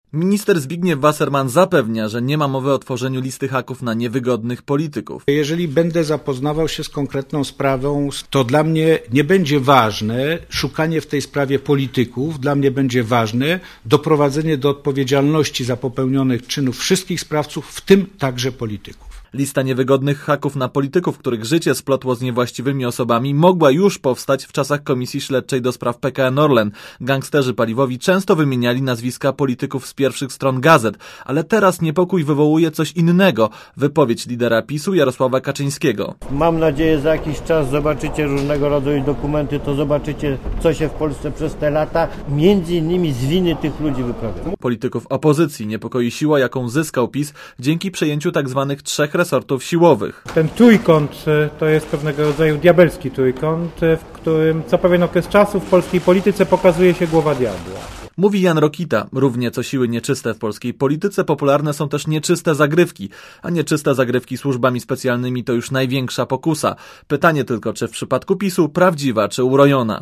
Relacja reportera Radia ZET
Zbigniew Wassermann powiedział na konferencji prasowej, że wbrew doniesieniom "Gazety", nie prowadzi żadnej akcji wymierzonej przeciwko jakimkolwiek politykom.